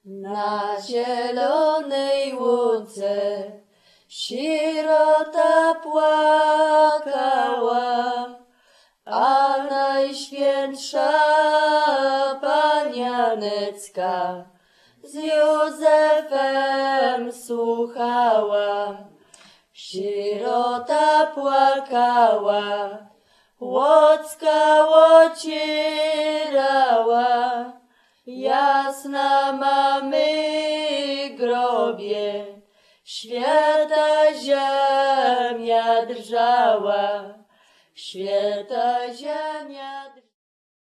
Na zielonej ł±ce sierota płakała The orphan was crying on the green meadow (A lyrical song)
The CD contains archival recordings made in 1993-2007 in the area of western Roztocze (Lubelskie region) and its surrounding villages.